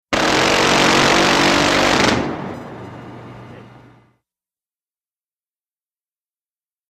WEAPONS - MACHINE GUNS HEAVY MK-15 PHALANX: EXT: Single rapid fire burst, extremely fast.